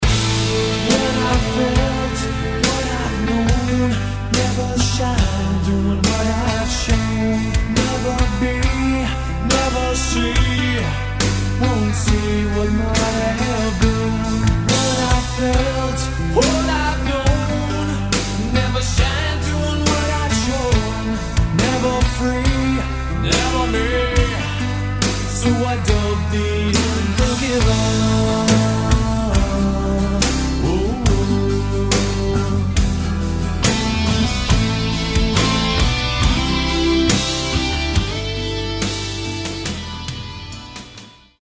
Рок рингтоны , Романтические рингтоны
Медленные , Heavy metal , Баллады